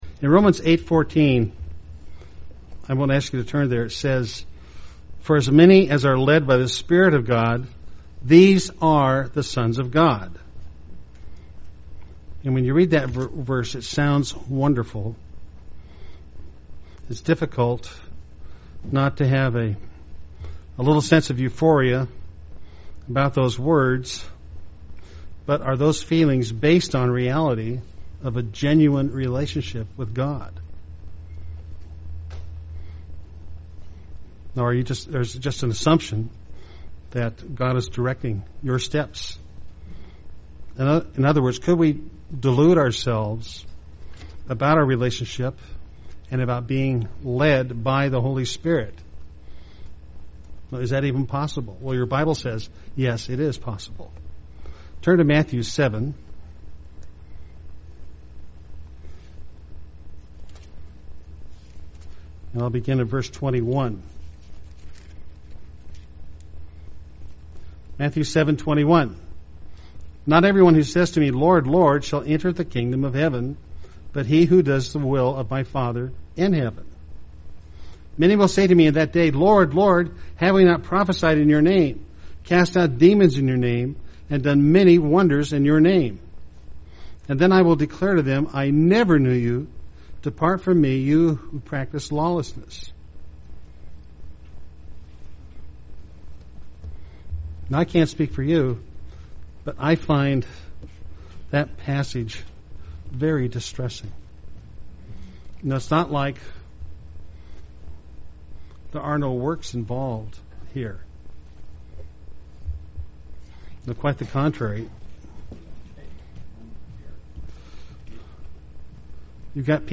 Given in Medford, OR
UCG Sermon Studying the bible?